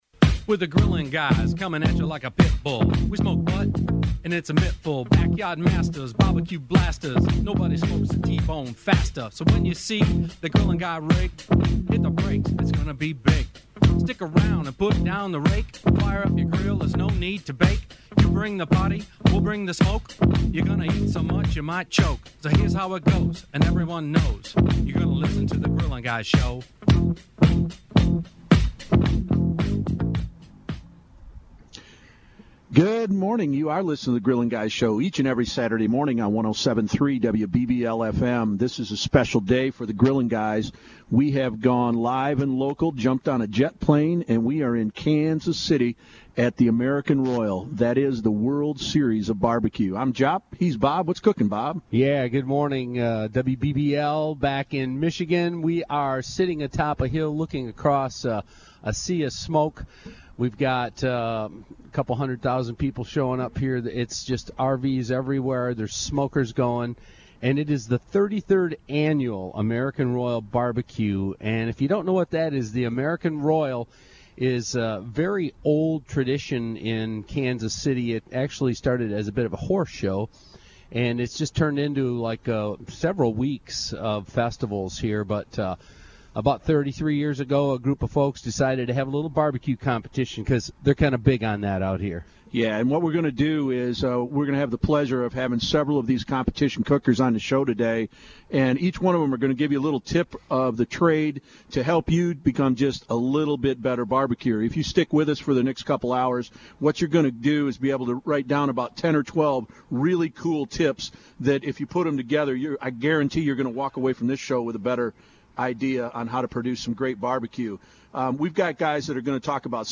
BBQ Radio for Grilling Fanatics!
Dudes are at the World Series of Barbecue!